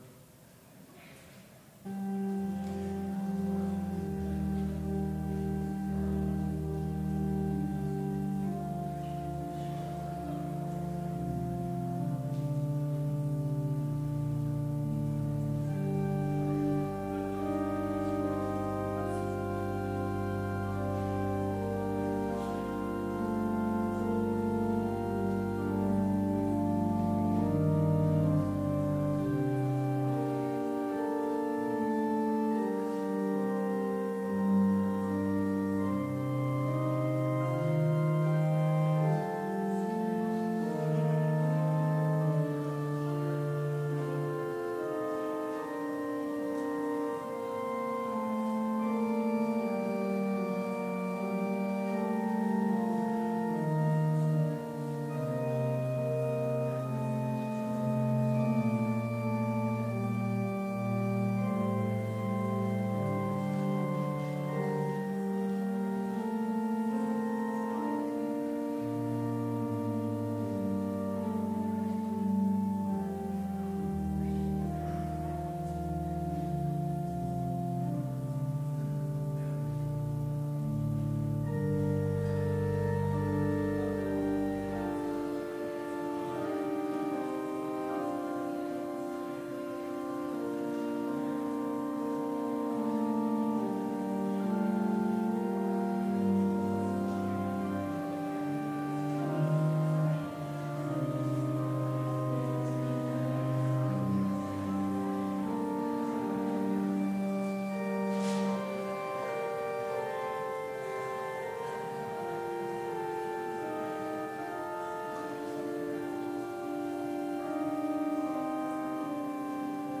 Complete service audio for Chapel - April 9, 2019
Complete Service
Hymn 283 - Glory Be To Jesus, Who in Bitter Pains
Devotion Prayer Hymn 301 - There is a Fountain Filled With Blood View Blessing Postlude